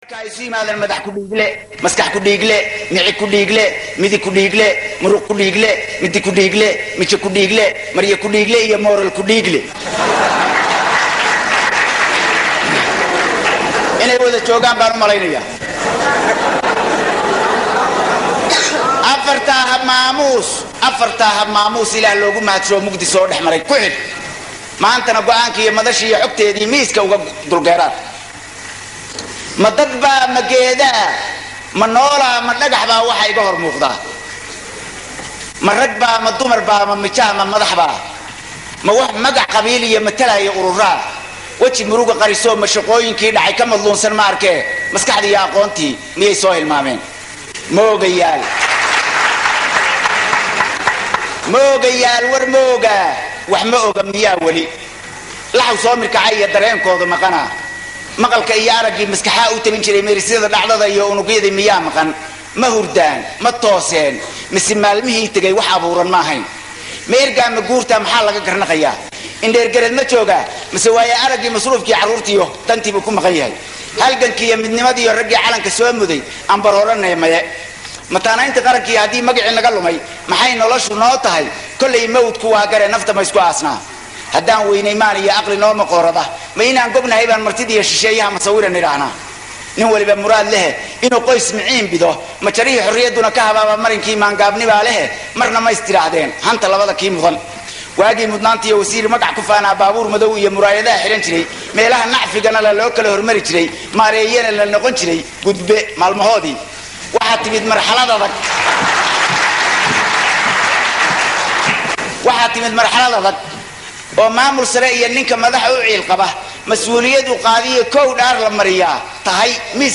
Dhegeyso: Gabay Ka Hadlaya Siyaasiga Somaliyeed oo uu Tiriyey Alaha u Naxariistee Abwaan C/qaadir Xersi Yam Yam 21 Sano Ka Hor